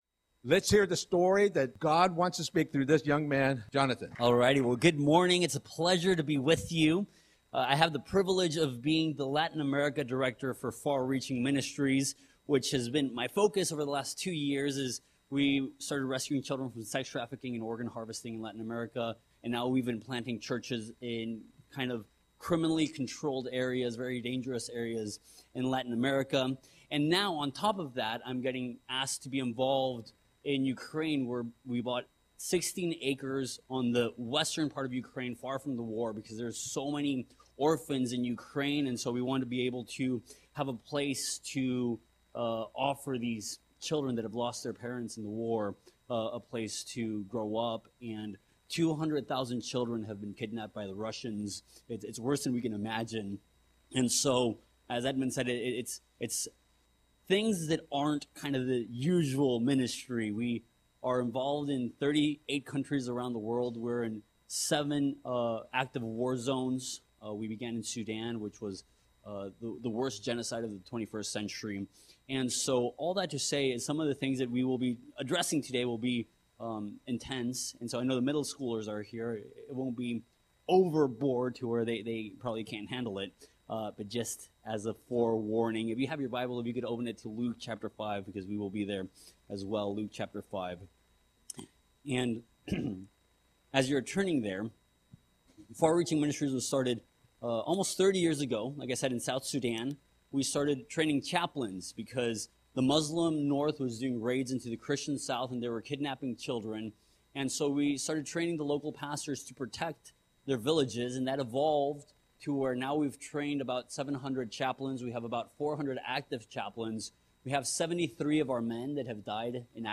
Guest Speaker: Far Reaching Ministries
Audio Sermon - November 3, 2024